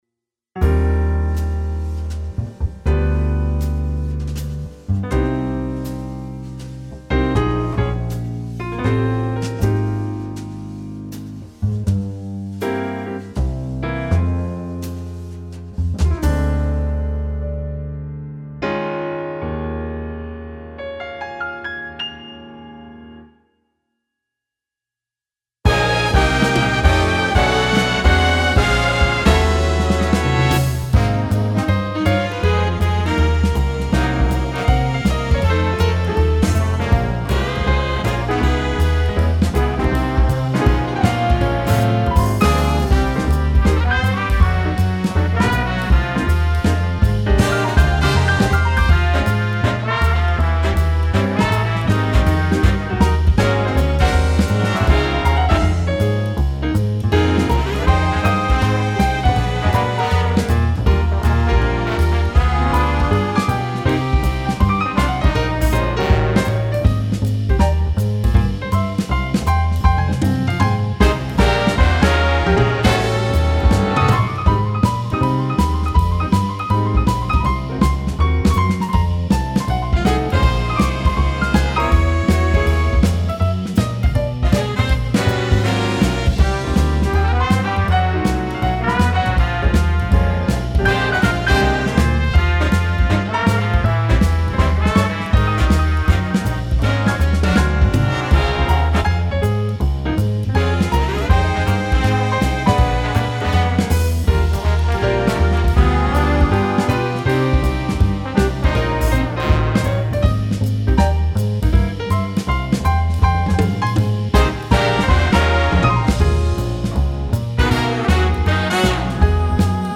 Задача - снять партию рояля, при условии его сбалансированности с ансамблем. В качестве упрощения задачи - отсутствие вокала.)